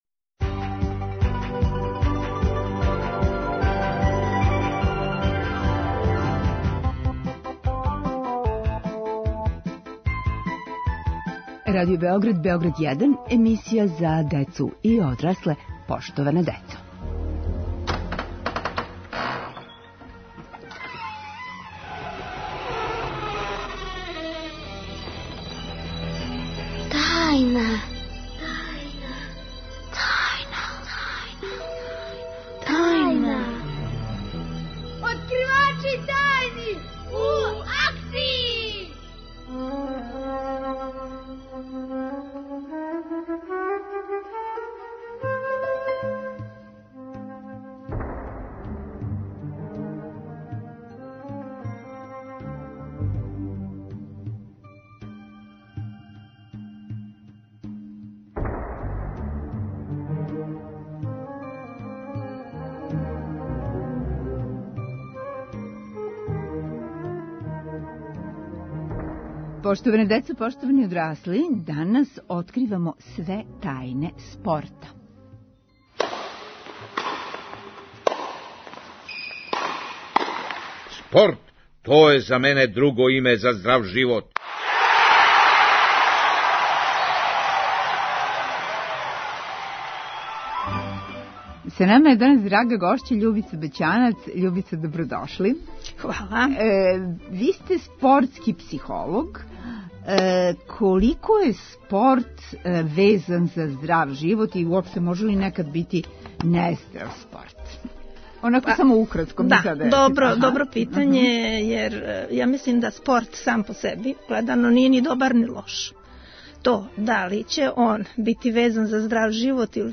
спортски психолог.